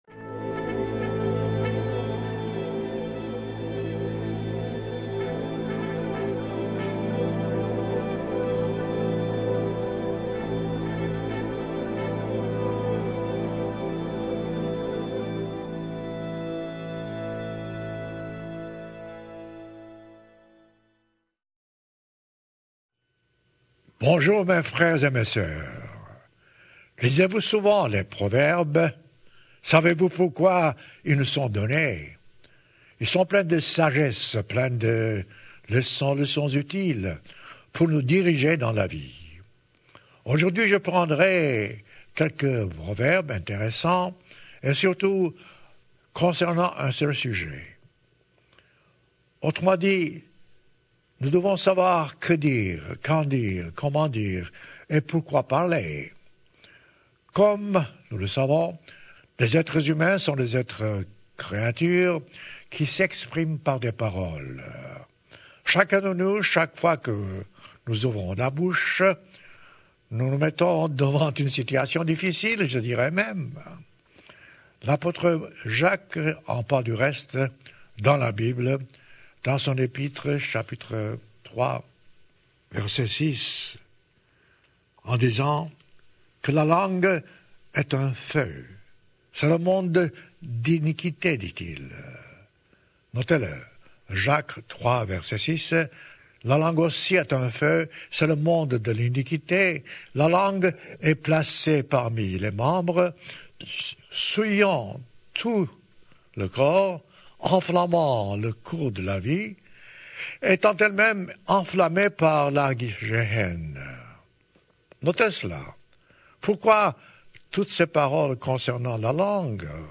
Ils sont pleins de leçons utiles pour nous diriger dans la vie. Dans ce sermon, nous étudierons plusieurs proverbes concernant la parole et notre façon de parler.